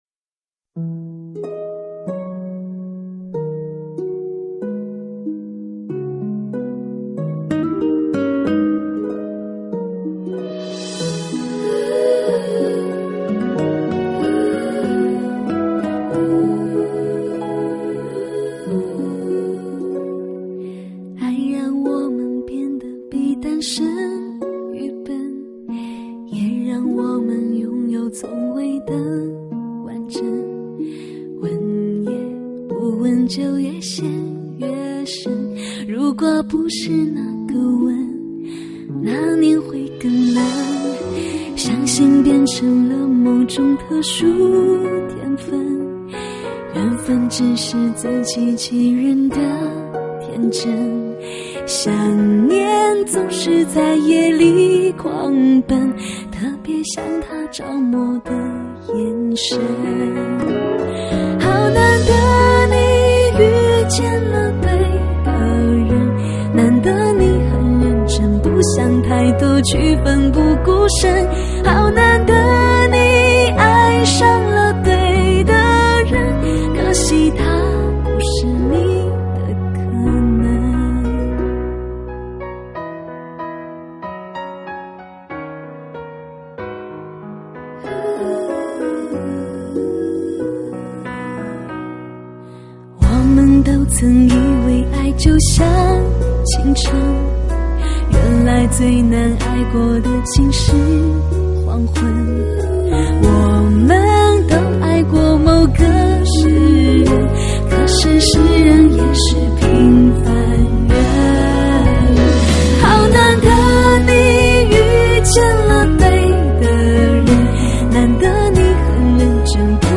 從敏感纖細到溫柔抒情再到搖滾爆發力
簡單輕柔的情歌充滿層次與氣質，洶湧澎湃的情歌更添溫柔厚度